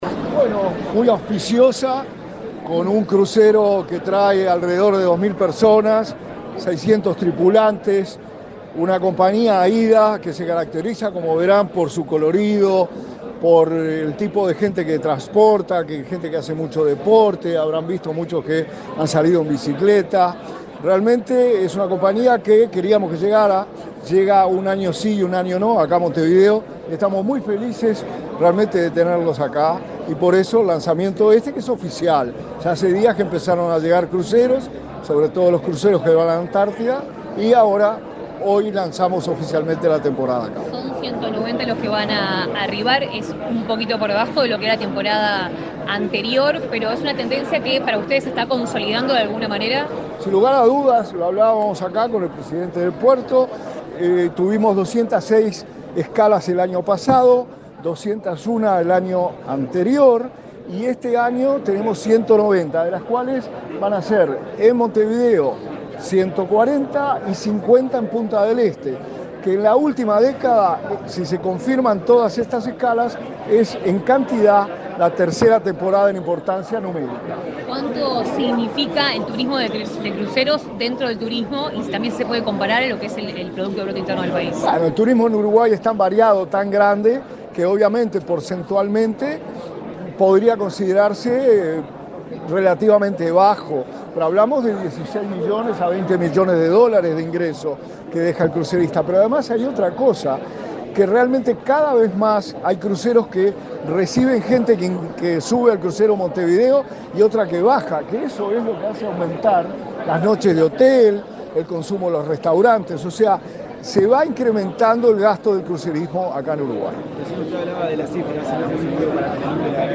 Declaraciones de autoridades en el puerto de Montevideo
Declaraciones de autoridades en el puerto de Montevideo 12/11/2024 Compartir Facebook X Copiar enlace WhatsApp LinkedIn El subsecretario de Turismo, Remo Monzeglio, y el presidente de la Administración Nacional de Puertos, Juan Curbelo, dialogaron con la prensa en el puerto de Montevideo, durante el lanzamiento de la temporada de cruceros.